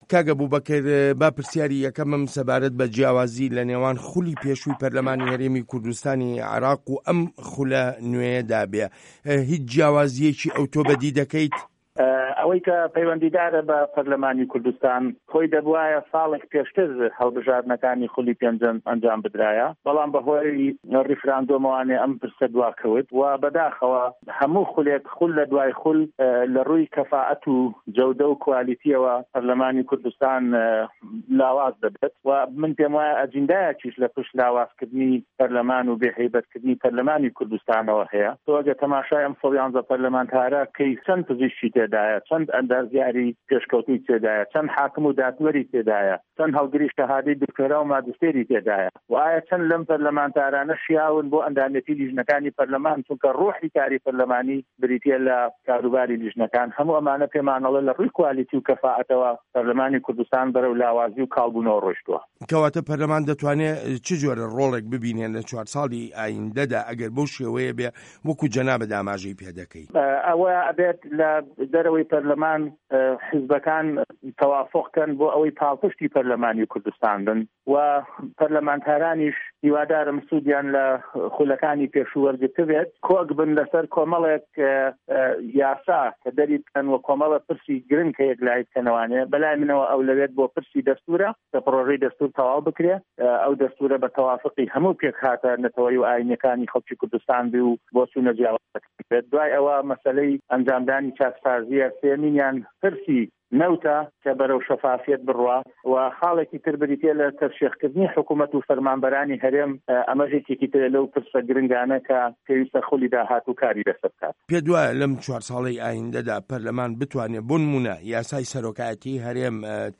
هه‌رێمه‌ کوردیـیه‌کان - گفتوگۆکان
وتتوێژ لەگەڵ ئەبوبەکر هەڵەدنی